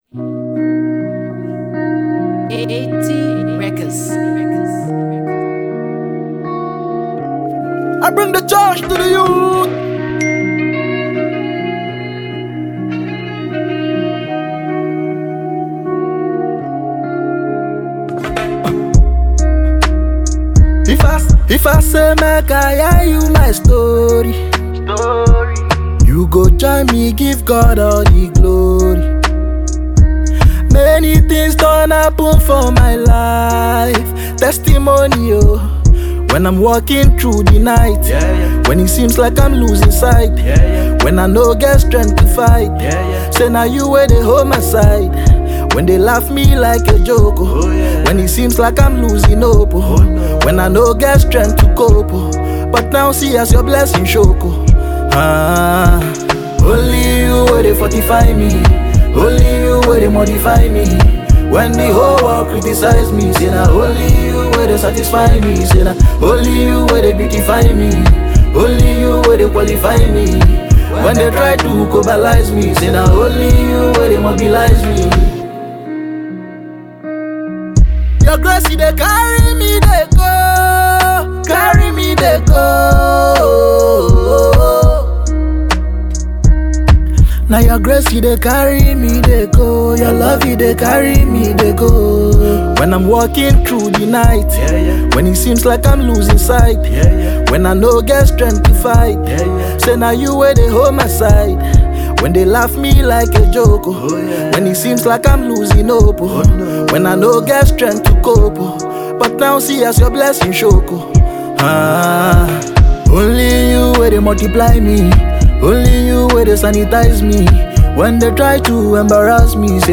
Afro beat singer